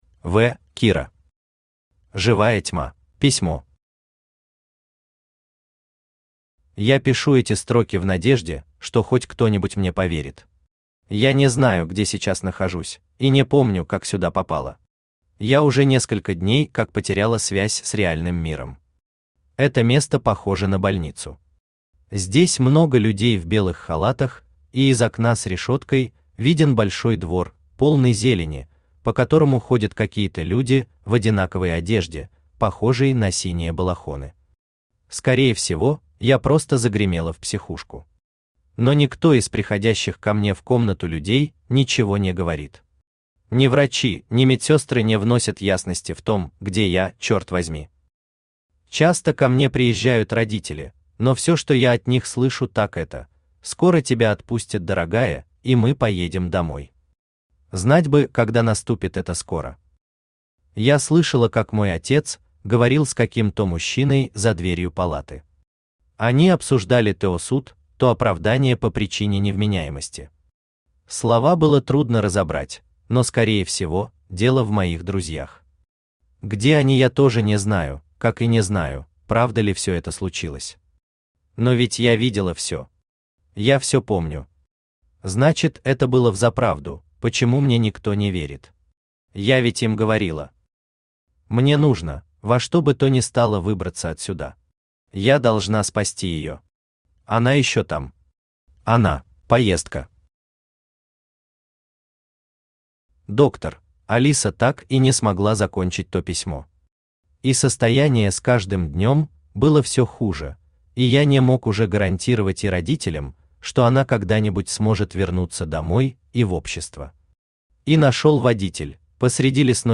Aудиокнига Живая тьма Автор В.Кира Читает аудиокнигу Авточтец ЛитРес.